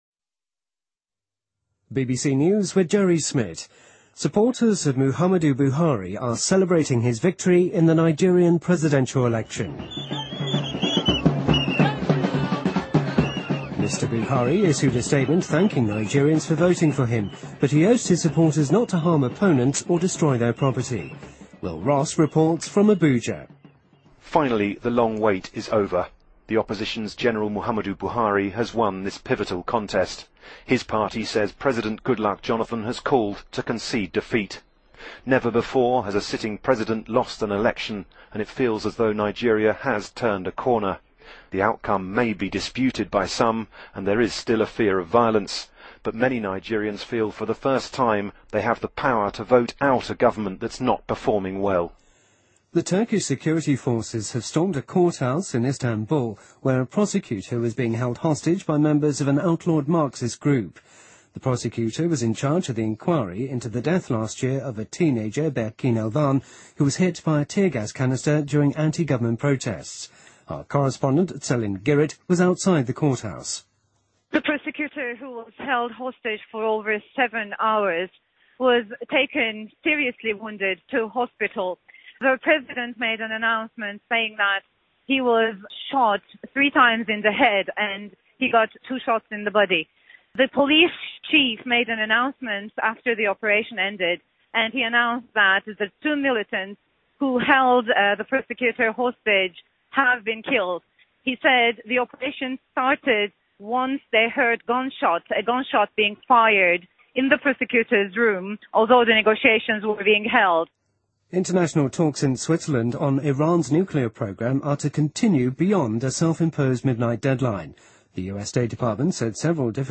BBC news,布哈里当选尼日利亚新总统